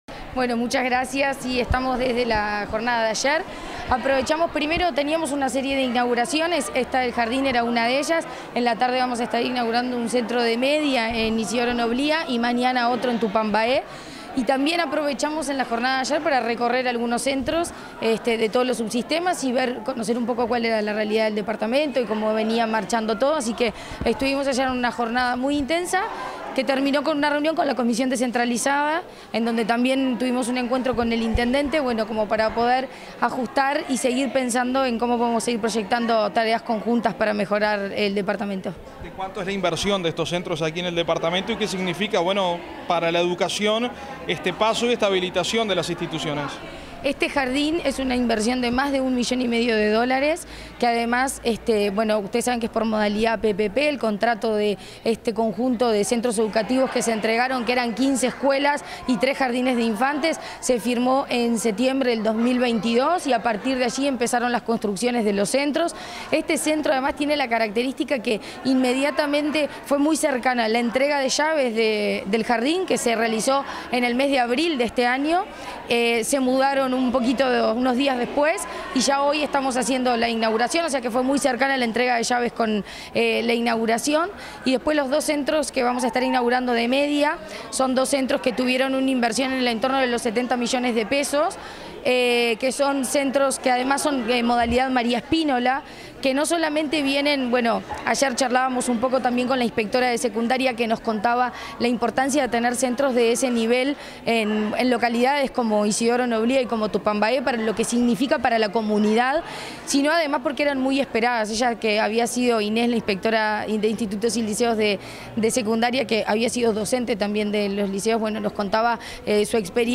Declaraciones de la presidenta de ANEP, Virginia Cáceres
Declaraciones de la presidenta de ANEP, Virginia Cáceres 06/06/2024 Compartir Facebook X Copiar enlace WhatsApp LinkedIn Tras la inauguración del edificio del jardín de infantes n.° 128, en la ciudad de Melo, y su nominación como Teresita Cazarré Egure, la presidenta del Consejo Directivo Central de la Administración Nacional de Educación Pública (ANEP), Virginia Cáceres, realizó declaraciones a la prensa.